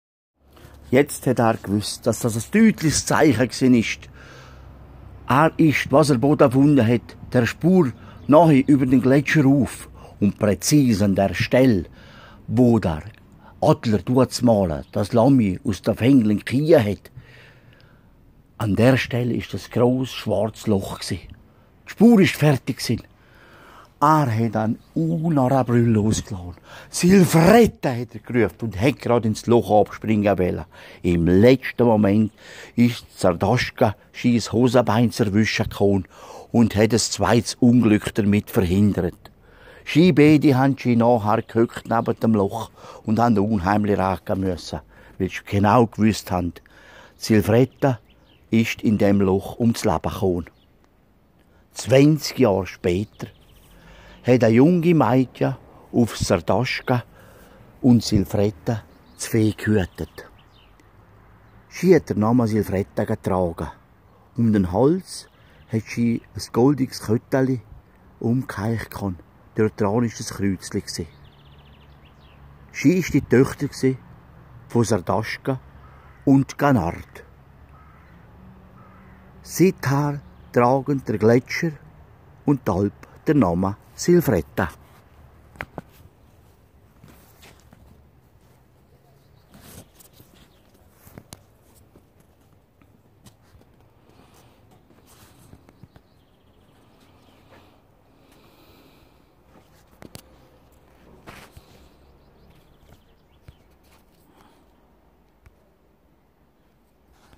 Im Heubett kann man sich einnisten und unter den lampenförmigen Lautsprechern eine Reise durch die Sagen der Region machen.